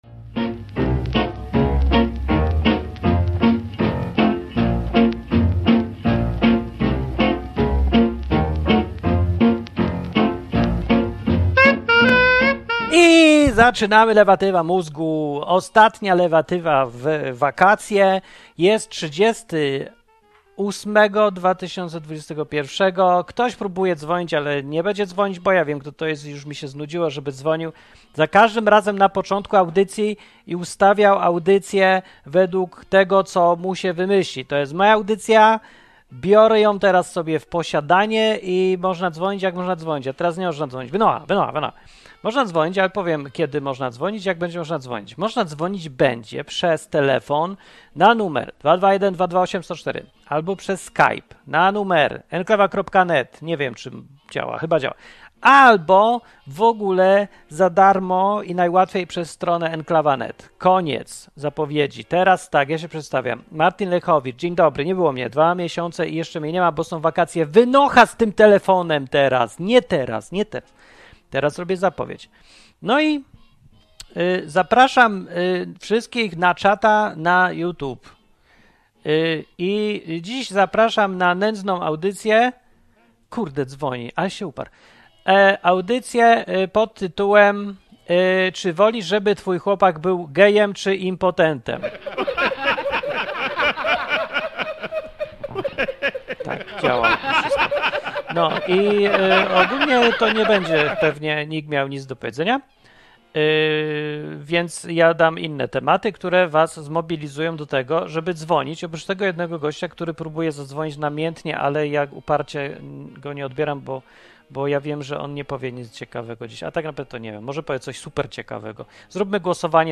Urzędnicy w Australii strzelają, żeby chronić przed wirusem, którego nie ma. A potem dzwoni słuchacz z pomysłem jak kapitalistycznie zarobić na ludziach z komunistycznymi ideałami. Naprawdę bardzo dziwne telefony w tej audycji były.